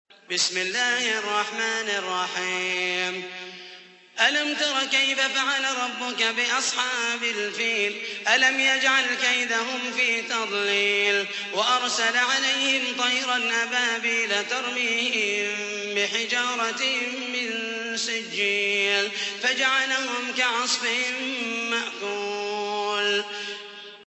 تحميل : 105. سورة الفيل / القارئ محمد المحيسني / القرآن الكريم / موقع يا حسين